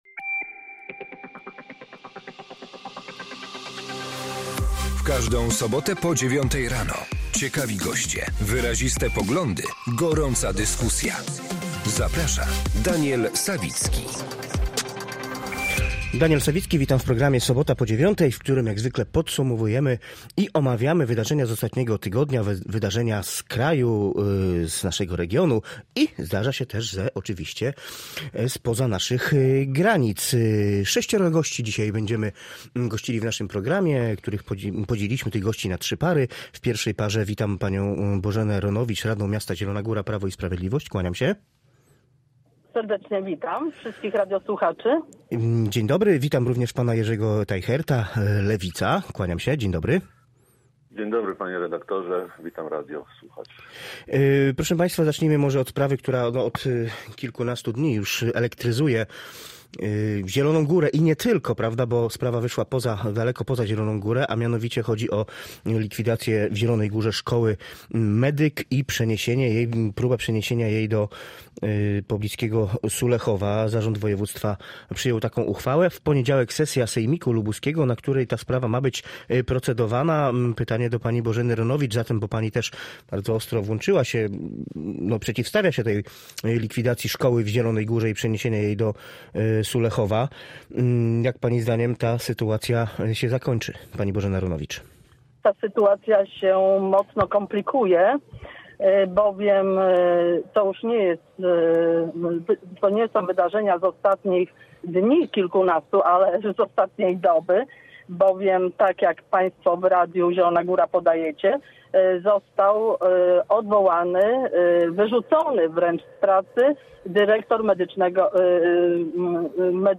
W programie Sobota po 9 rozmawiali: